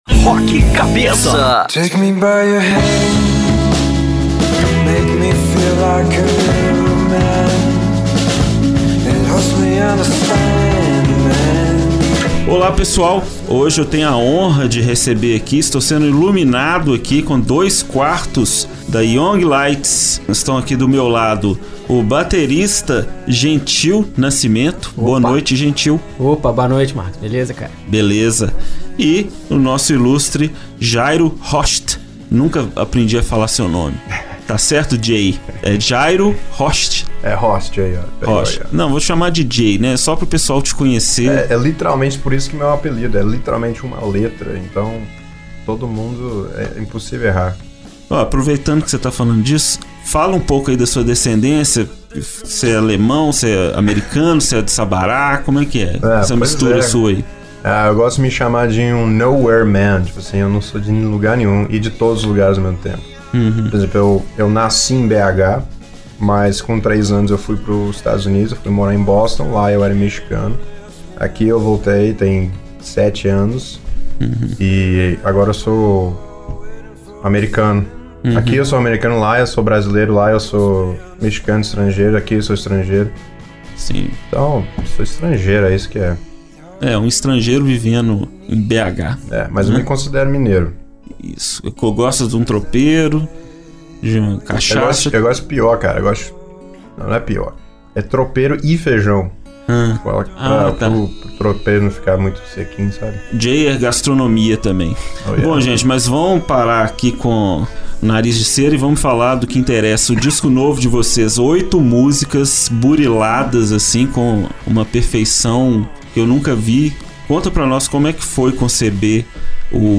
Os vocais pungentes
atmosfera etérea